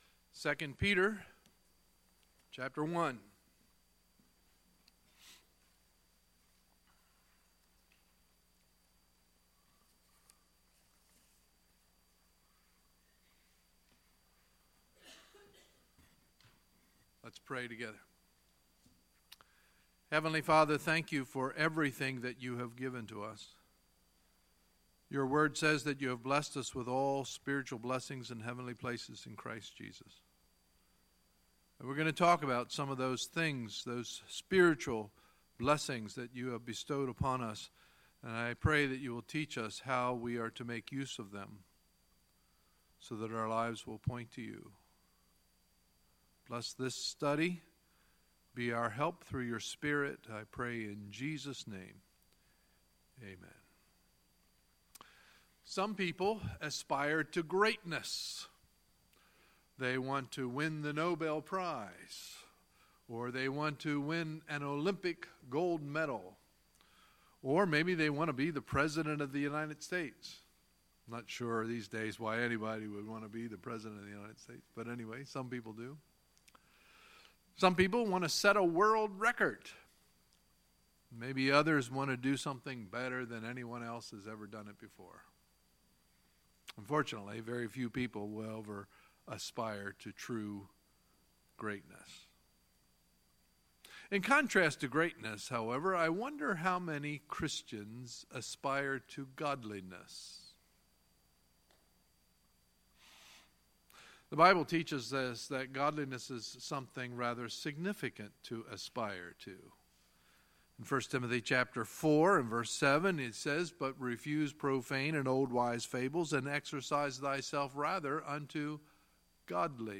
Sunday, October 8, 2017 – Sunday Morning Service